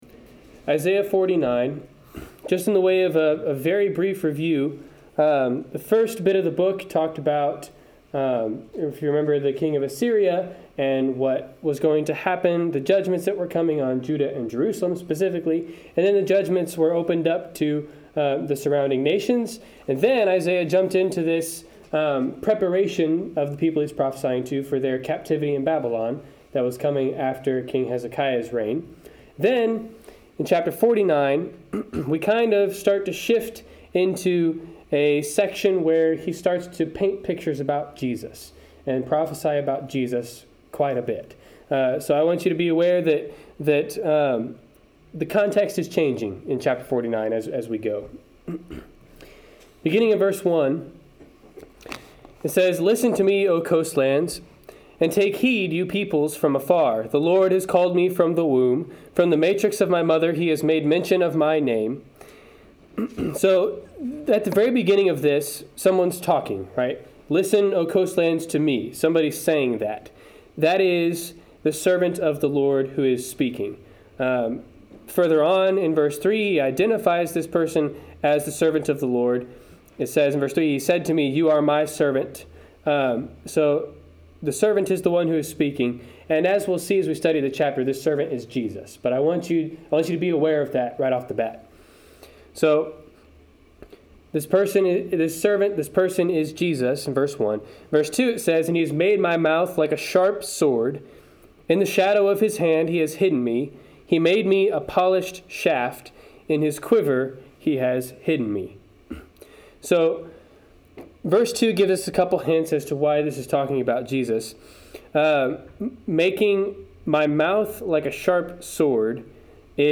Isaiah 49-50 Service Type: Wednesday Night Class Download Files Notes Notes Notes Notes Topics